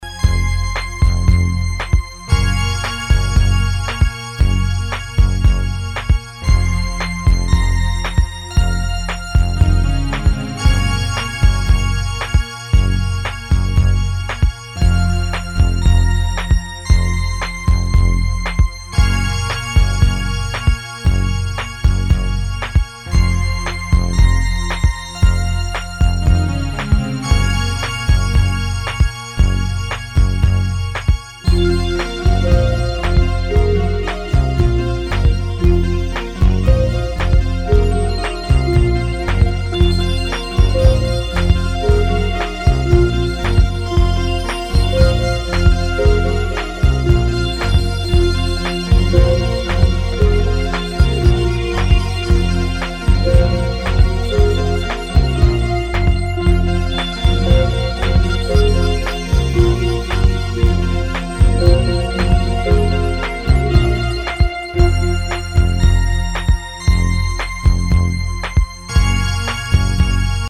Disco Electro House